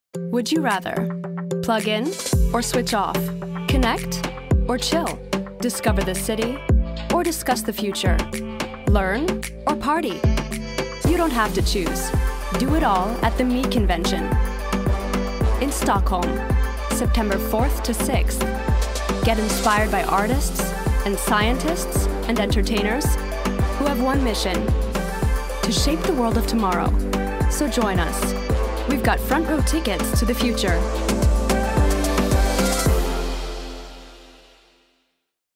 hell, fein, zart, sehr variabel
Mittel minus (25-45)
American, French
Commercial (Werbung)